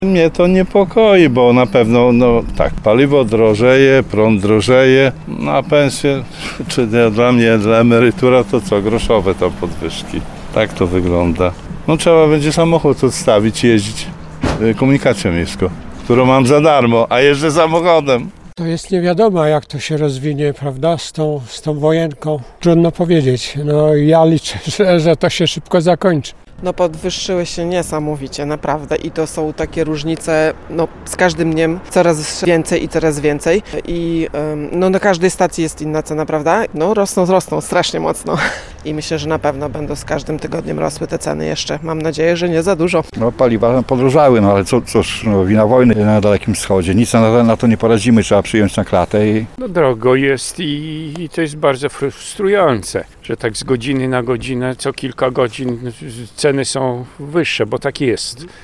sonda-ok.mp3